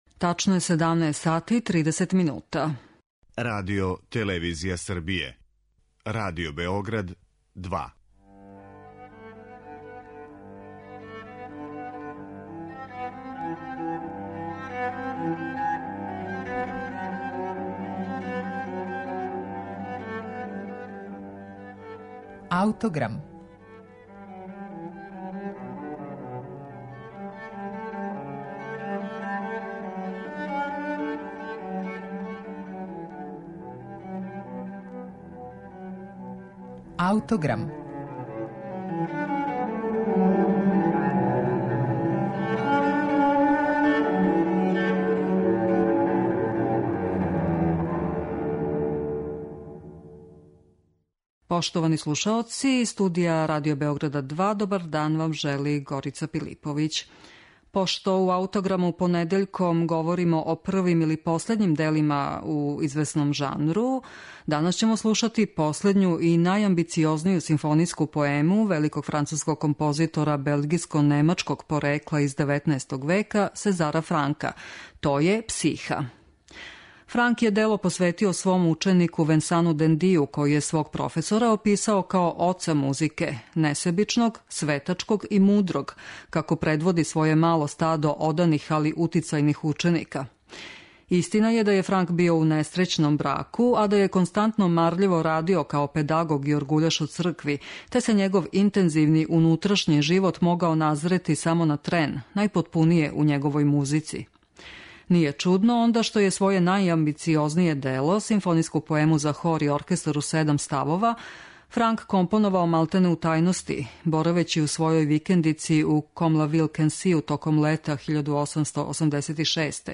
Симфонијска поема 'Психа' Сезара Франка